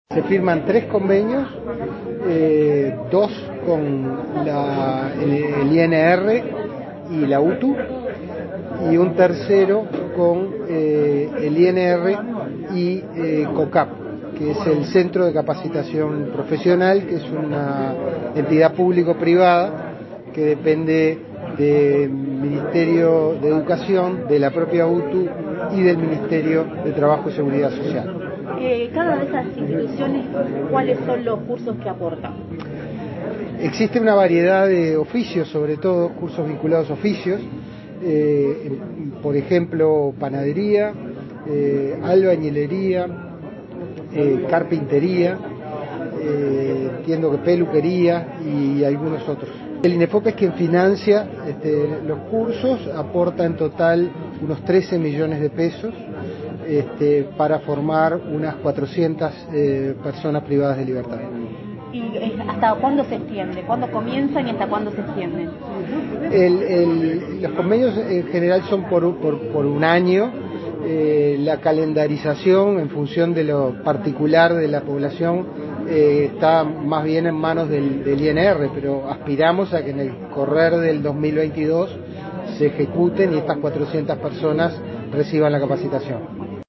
Entrevista al director de Inefop, Pablo Darscht
El director del Instituto Nacional de Empleo y Formación Profesional (INEFOP), Pablo Darscht, dialogó con Comunicación Presidencial sobre la firma de convenios con representantes del Instituto Nacional de Rehabilitación, UTU y el Consejo de Capacitación Profesional, para la capacitación en oficios y acreditaciones de saberes a más de 400 personas privadas de libertad.